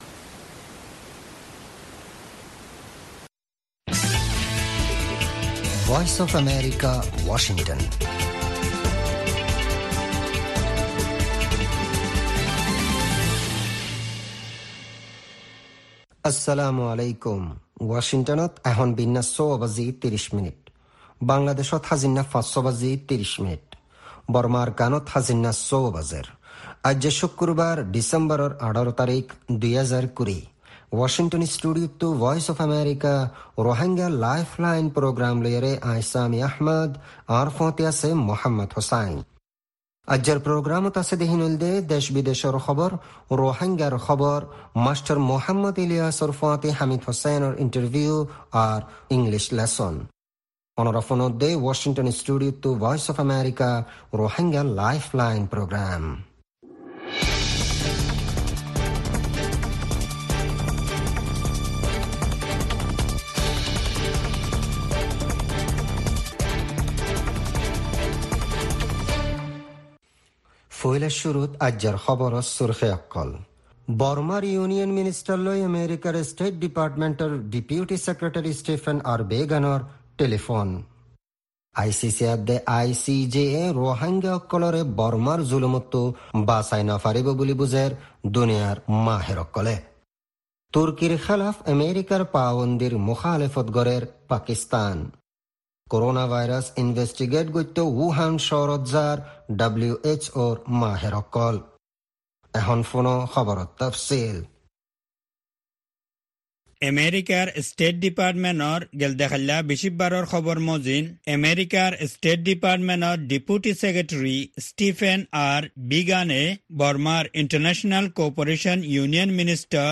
Rohingya “Lifeline” radio
News Headlines